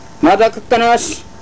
この町のことばをお聴きいただけます